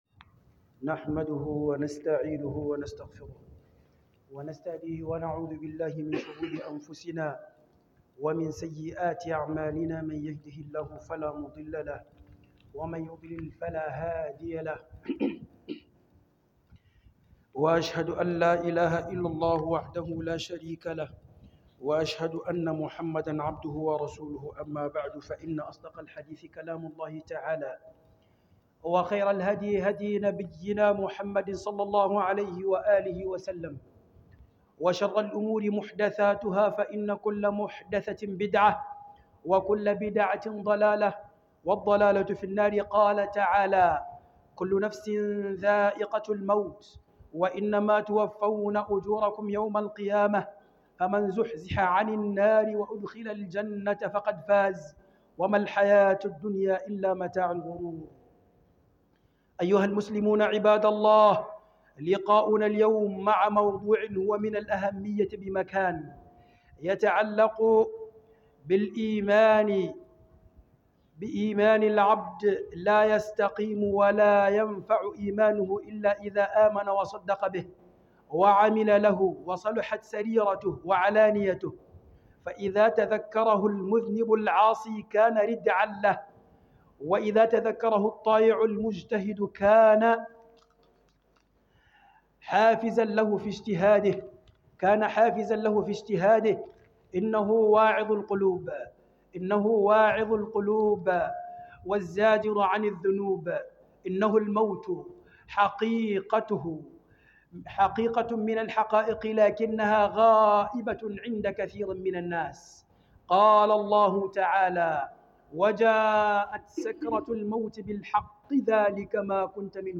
KHUDUBA